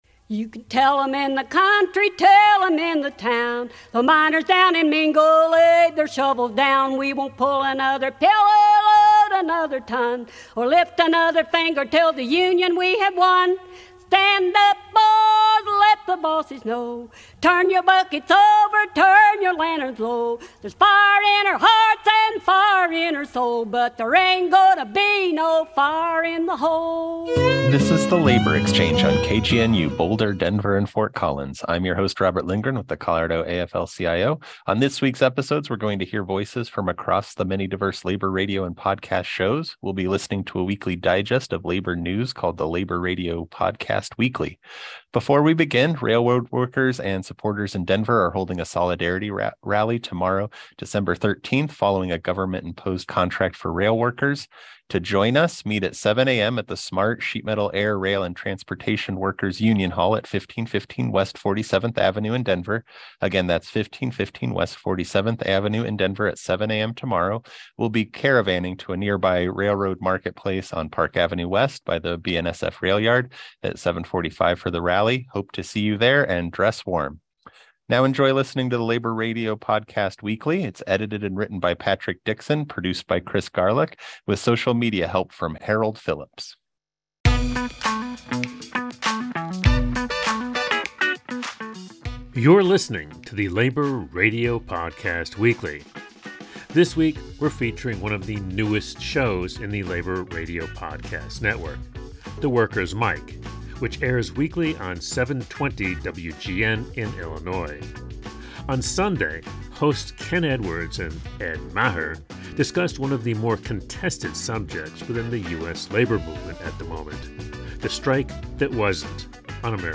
On this week’s episode of the Labor Exchange we’re going to be hearing voices from across the many diverse labor radio and podcasts shows. We’ll be listening to a weekly digest of labor news and voices called the Labor Radio Podcast Weekly.